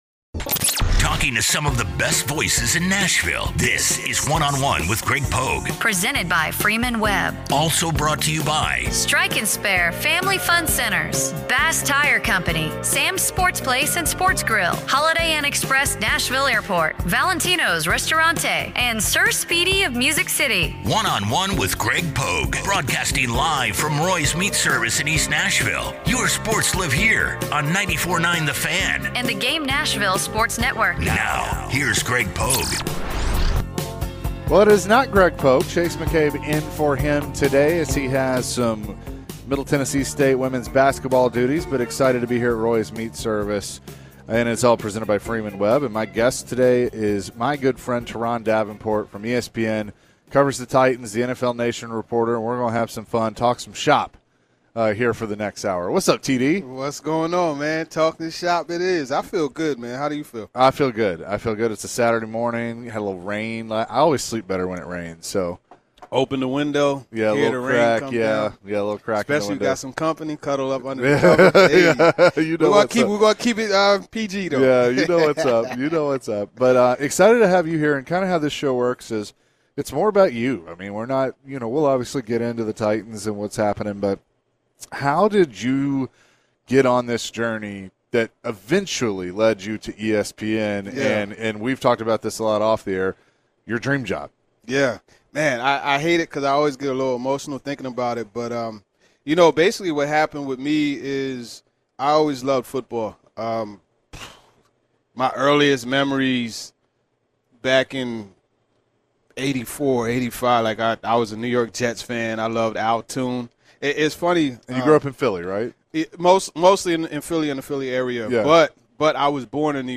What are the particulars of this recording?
LIVE in Studio!